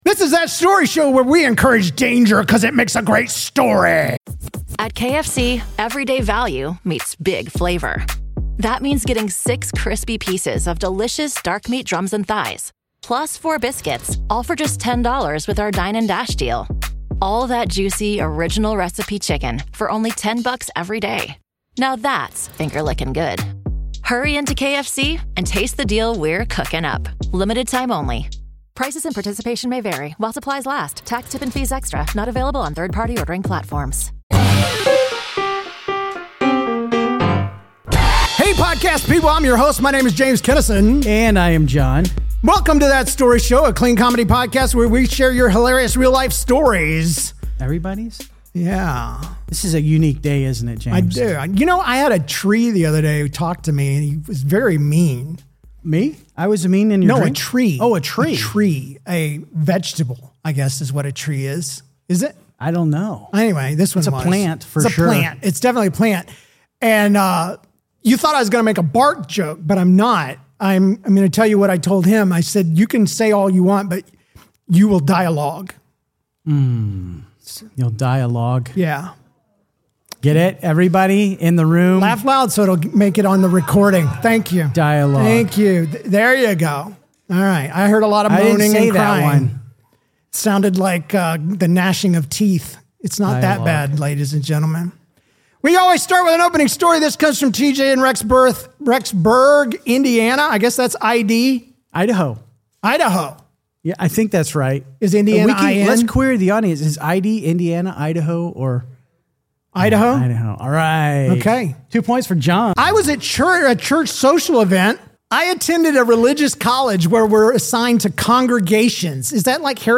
Live from Atlanta, cringe-worthy listener stories: accidental punches, childhood confessions, a loud fart, a bloody mishap, and a thumb lost to a saw.